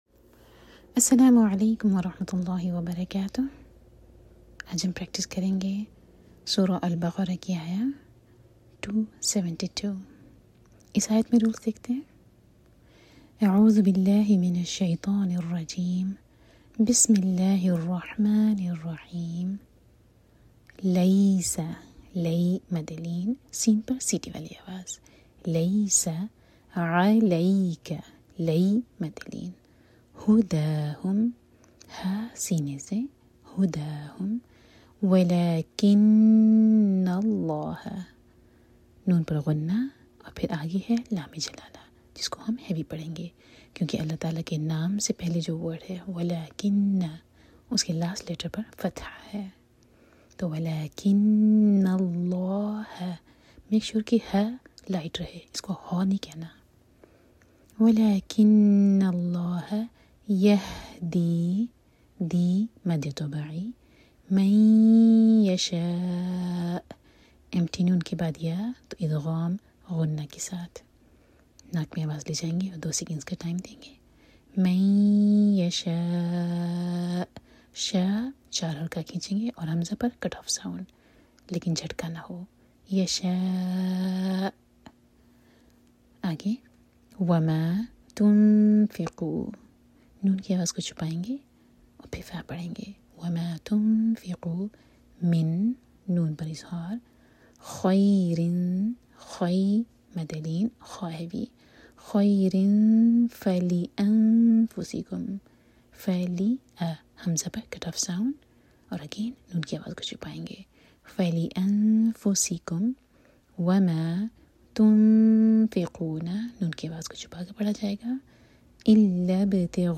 Tajweed Lessons
Lesson